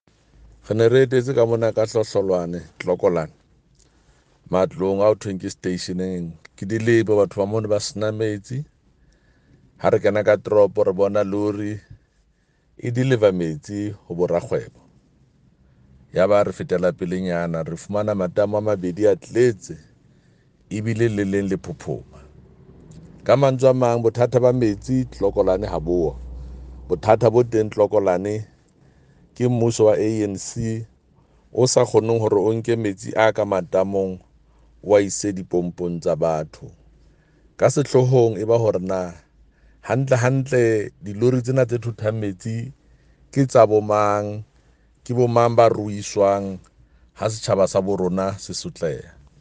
Sesotho soundbites by Jafta Mokoena MPL.